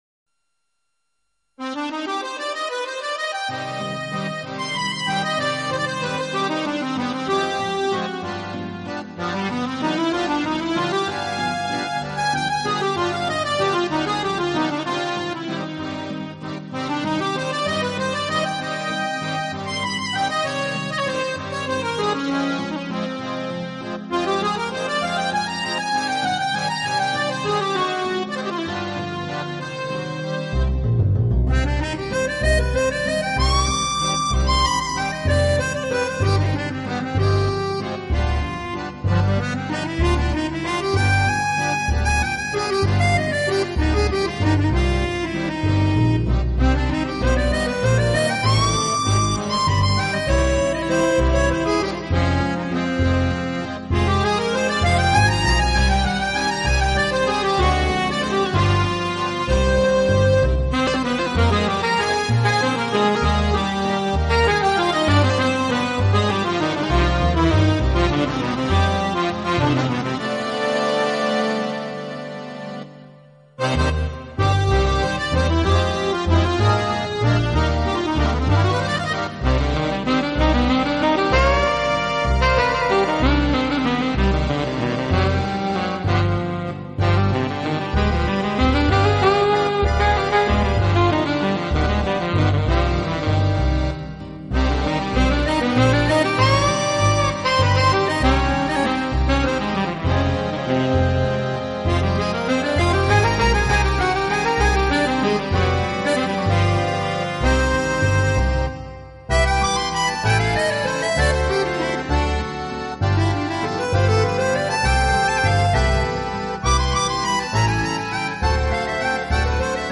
俐落的吉它
配上超重低音的牛筋
浪漫的手风琴
和感性的萨士风
热情浪漫舞曲
俐落的吉它，配上超重低音的牛筋、浪漫的手风琴和感性的萨士风，